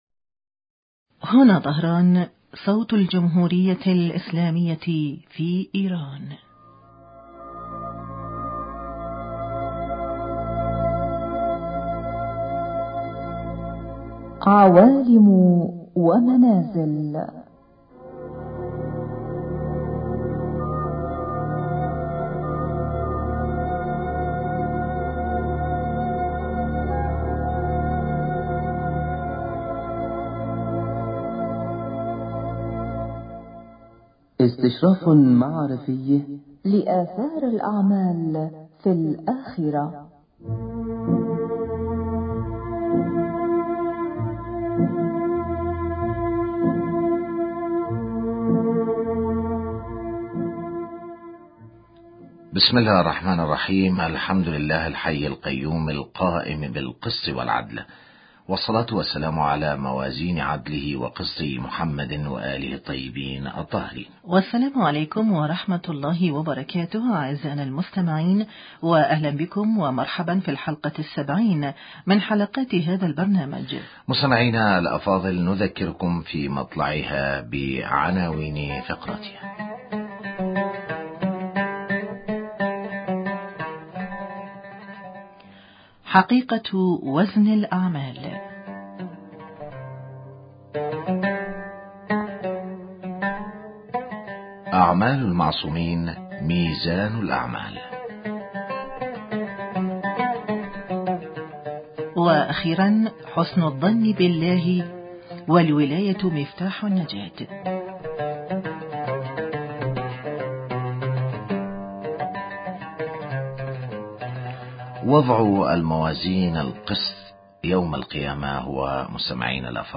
حقيقة وزن الاعمال أو وزن اصحابها يوم القيامة حوار